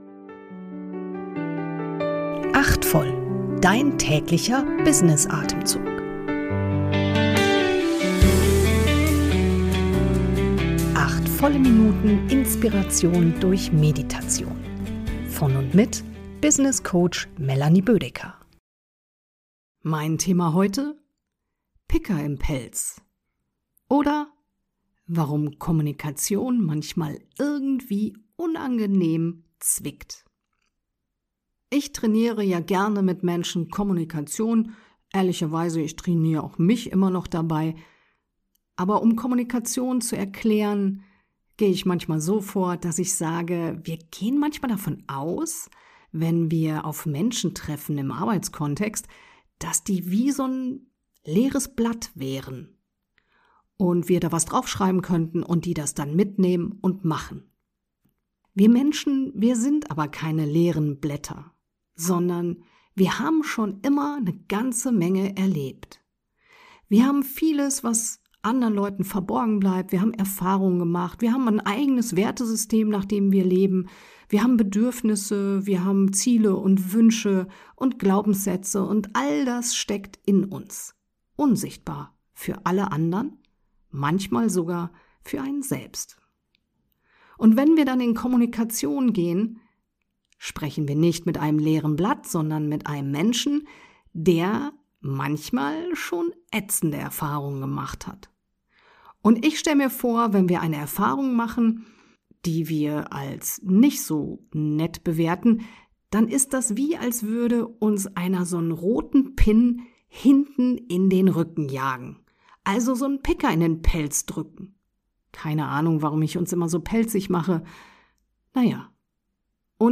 geleitete Kurz-Meditation.